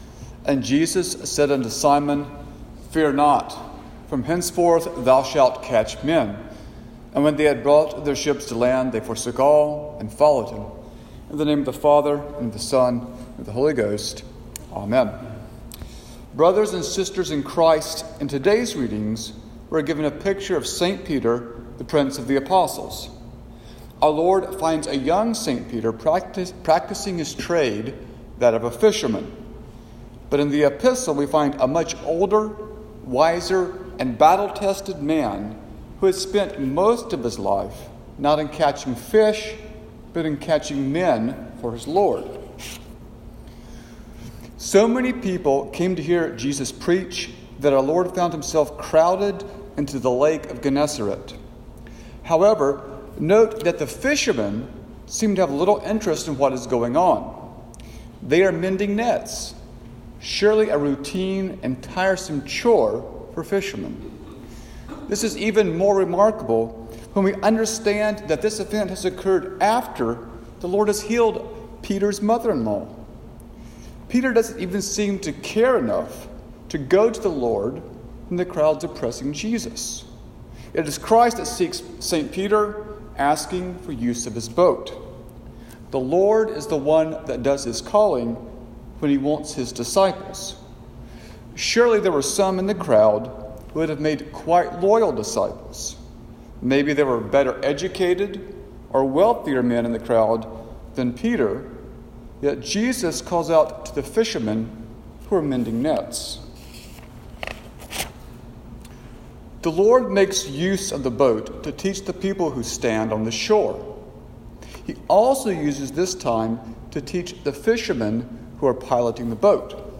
Sermon-for-Trinity-5.m4a